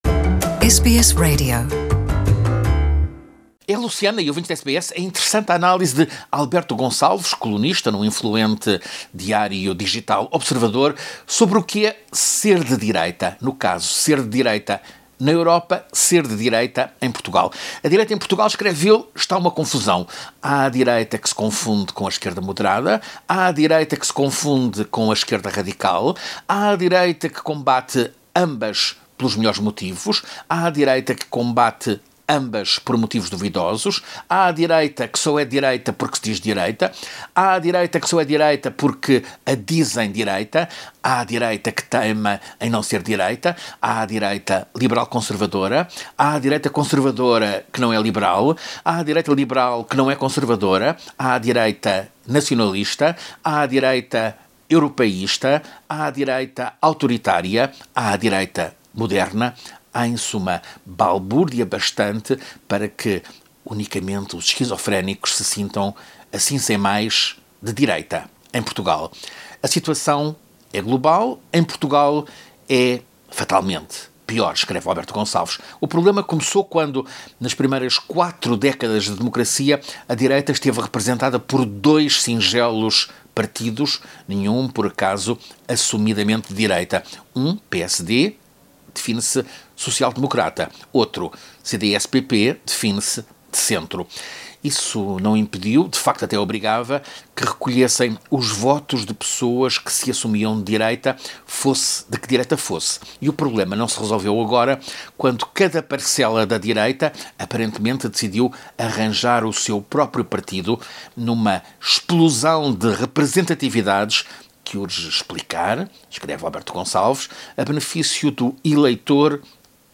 crônica